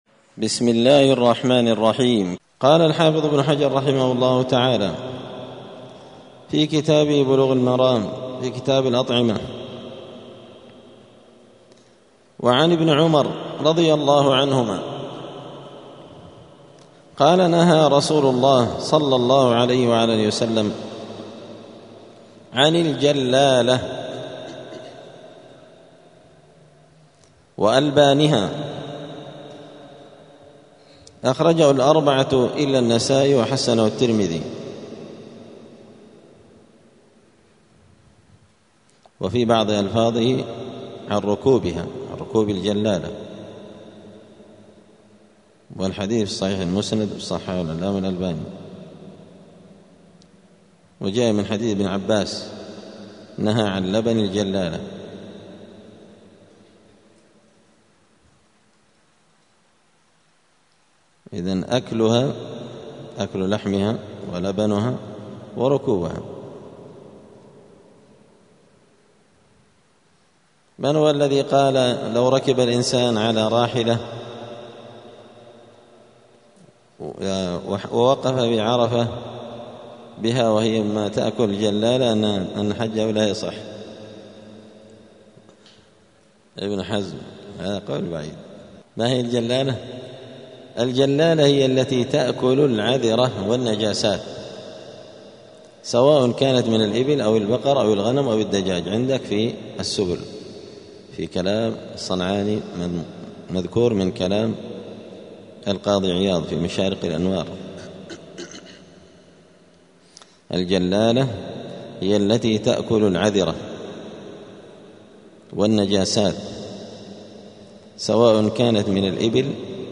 *الدرس الخامس (5) {حكم أكل الجلالة}*